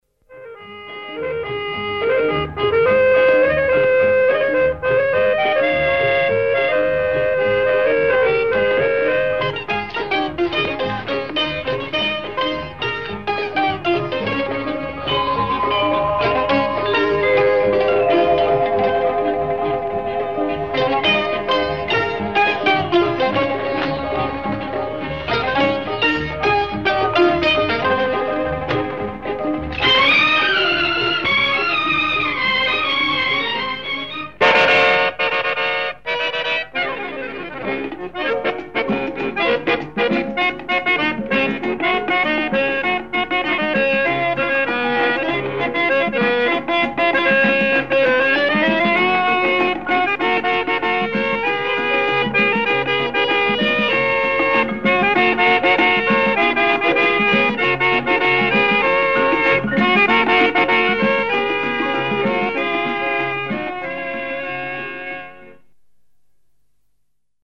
Artist: Instrumental